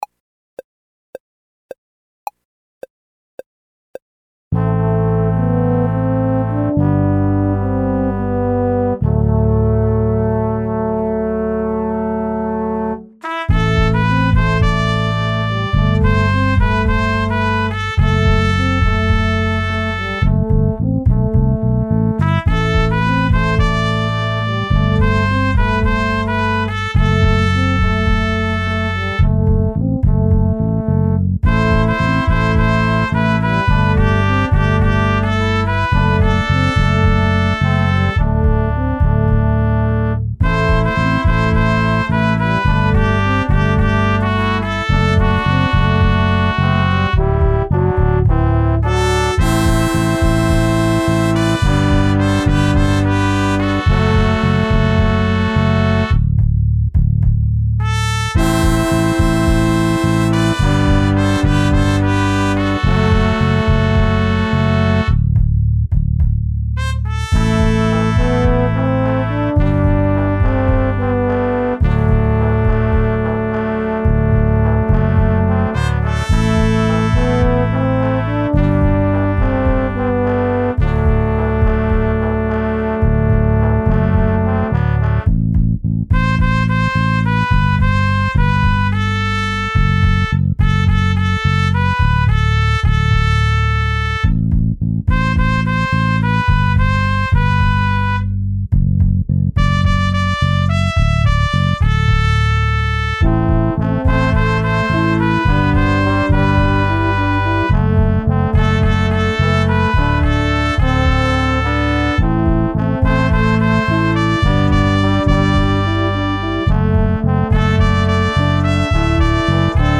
Trp 1 Trp 2 Pos 1 Pos 2 Horn BDrum Lyr Sax